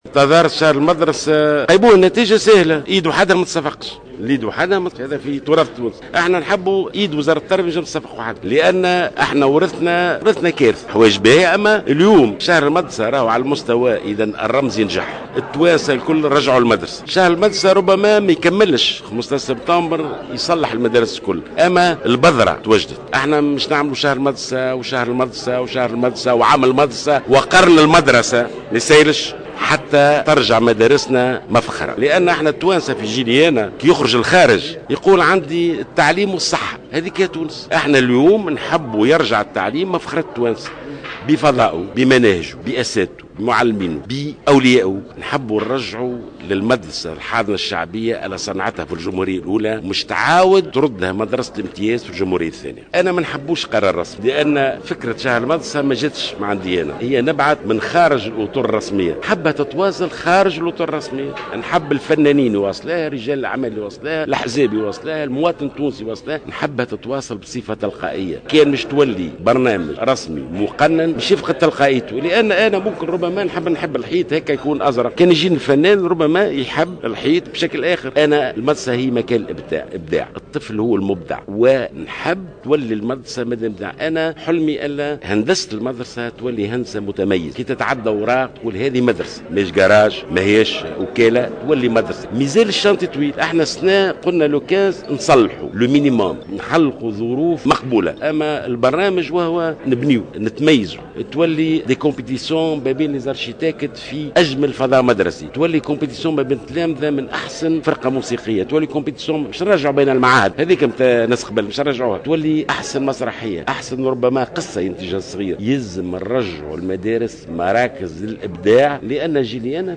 قال وزير التربية ناجي جلول في تصريح لجوهرة أف أم اليوم الخميس 20 أوت 2015 على هامش ندوة صحفية انعقدت بضاحية البحيرة بالعاصمة إن الوزارة بصدد مراجعة الزمن المدرسي دون فرض رأي على آخر على حد تعبيره.